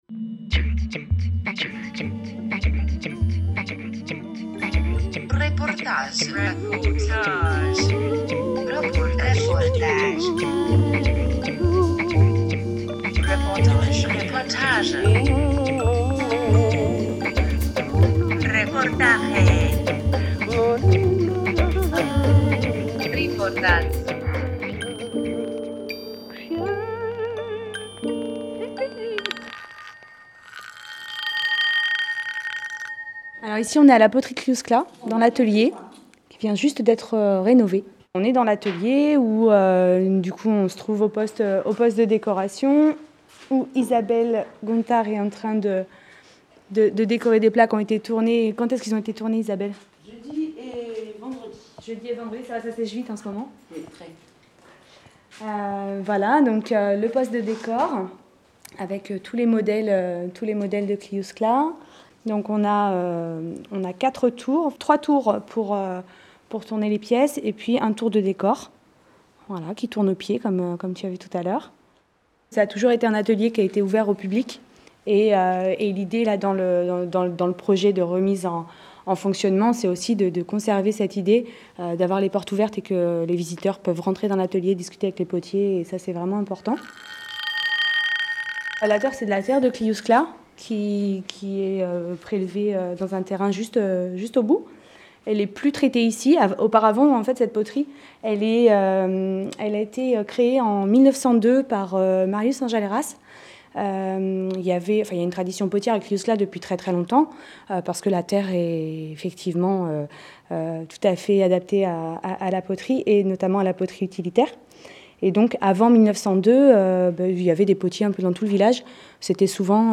Trois petits tours et puis revient : reportage à la fabrique de poterie de Cliousclat
18 octobre 2017 17:35 | Interview, reportage